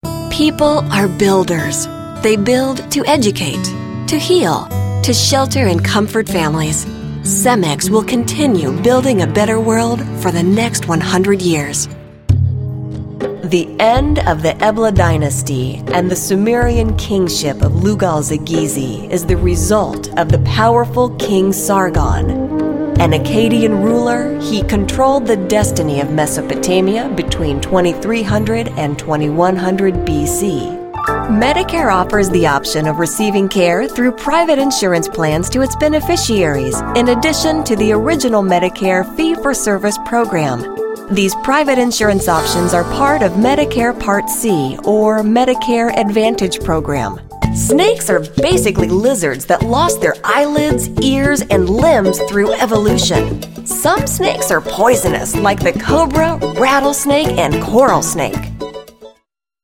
Professional female voiceover specializing in corporate and technical narration.
middle west
Sprechprobe: Industrie (Muttersprache):